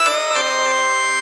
O SYN 2   -L.wav